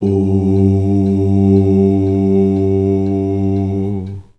Index of /90_sSampleCDs/Voices_Of_Africa/LongNoteSustains
18_01_Ooo_G.WAV